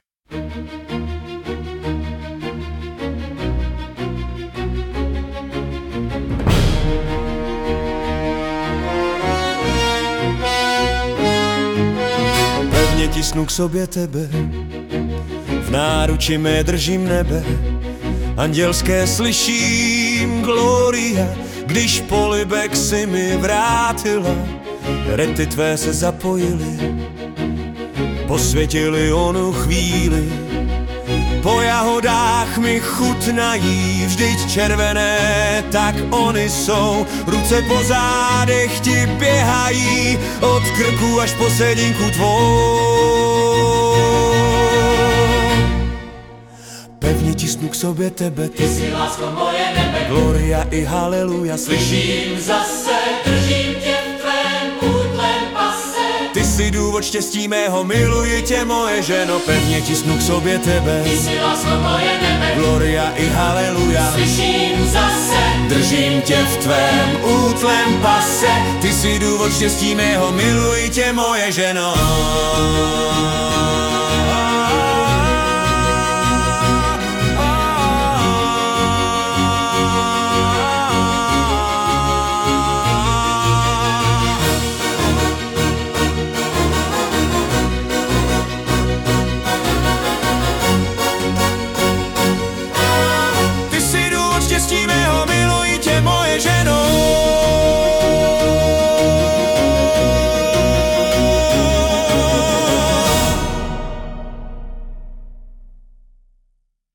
hudba, zpěv: AI
wow, tak to je mohutný vstup - ta hudba je dokonalá!
napětí houslí a skvělé zabarvení hlasu